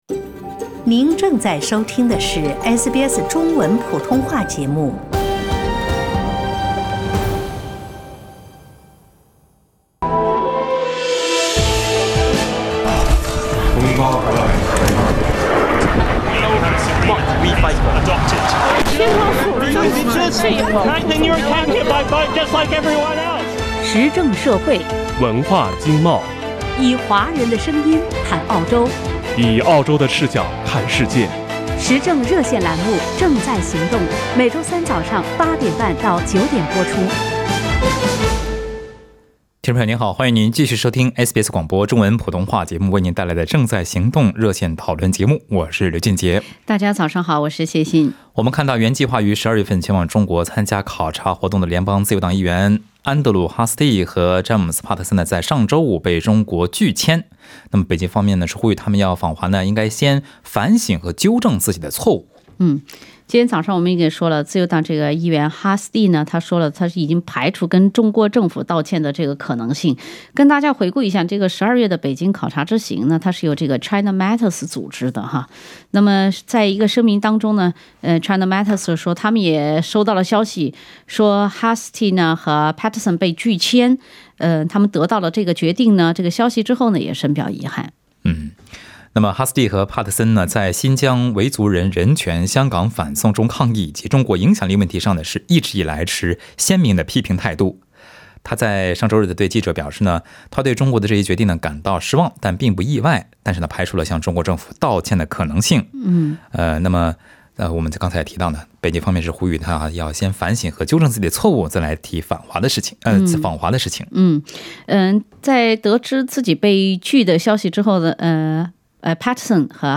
听众在本期《正在行动》节目中表达了对此次拒签事件的看法。